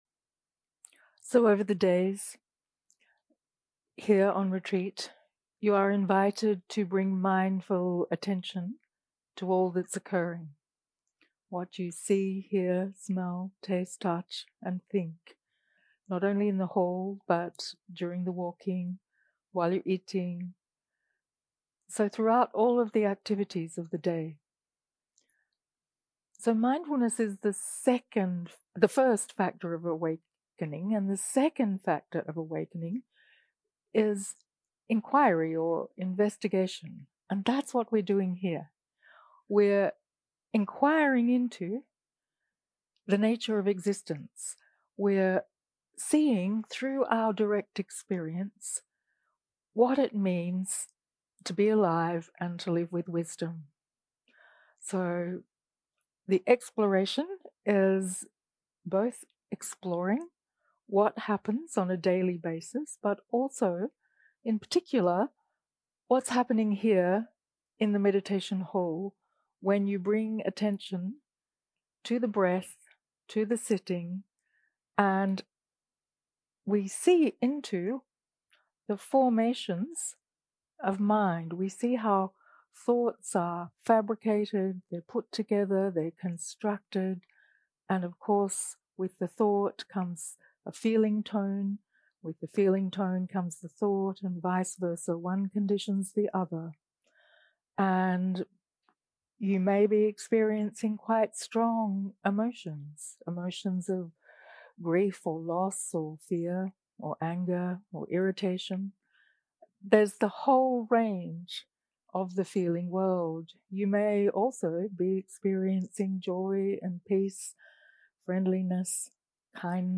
0:00 0:00 סוג ההקלטה: Dharma type: Inquiry שפת ההקלטה: Dharma talk language: English